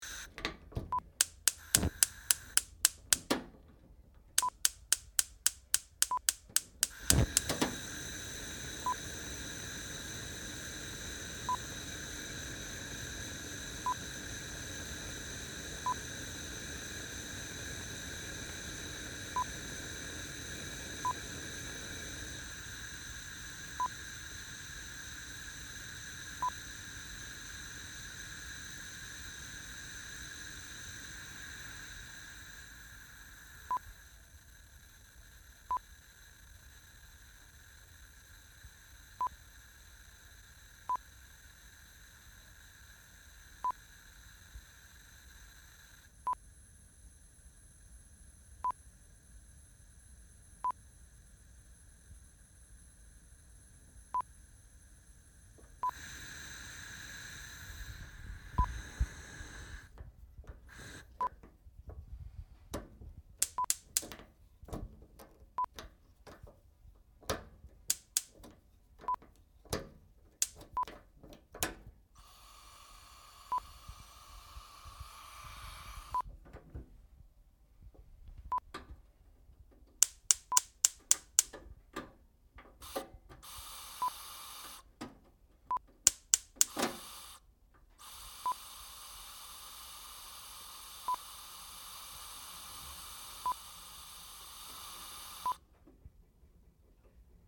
Cooker Gas Hob Turn on and Ignite (Sound FX)
Cooker Gas hob being switched on and ignited with electric spark. With some handle of the cooker knob that controls the gas. Can hear the gas burning and hissing and various strengths and levels.
Cooker_GasHob_plip.mp3